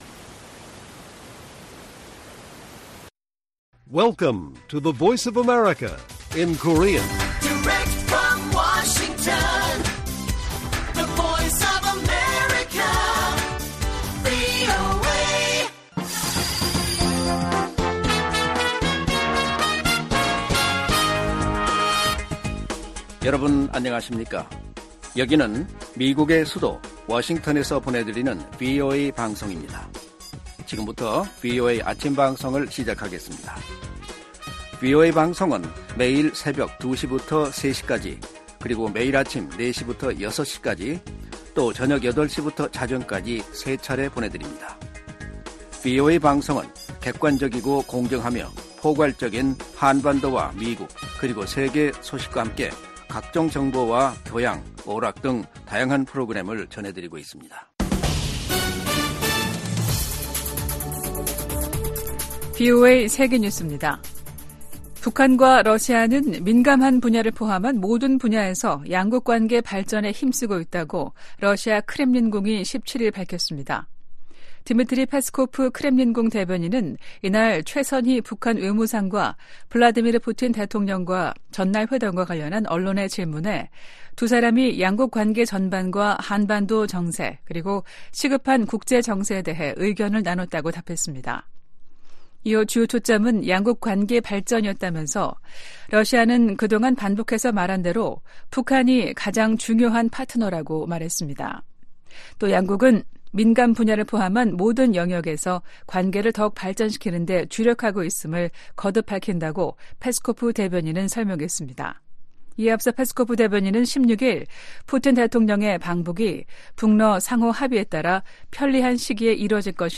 세계 뉴스와 함께 미국의 모든 것을 소개하는 '생방송 여기는 워싱턴입니다', 2024년 1월 18일 아침 방송입니다. '지구촌 오늘'에서는 2023년도 중국 경제성장률이 5.2%로 나온 소식 전해드리고, '아메리카 나우'에서는 공화당 대통령 후보 경선이 뉴햄프셔주에서 이어지는 이야기 살펴보겠습니다.